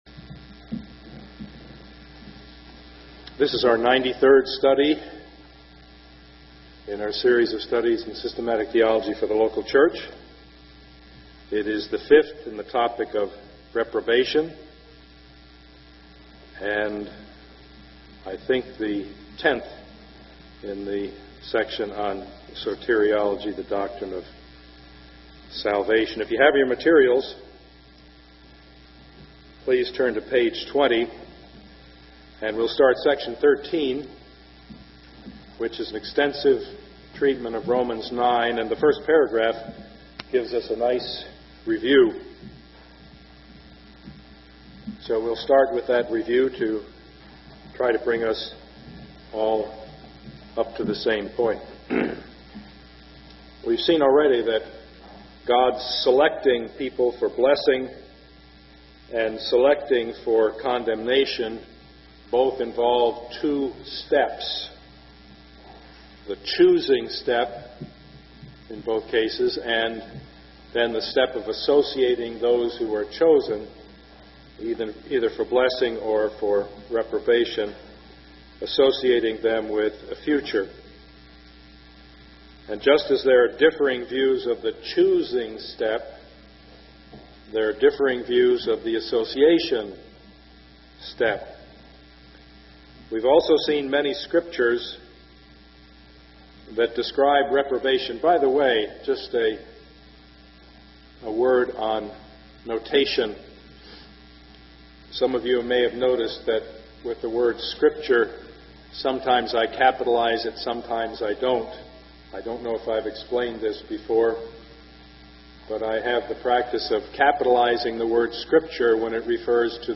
Service Type: Sunday morning
Sermon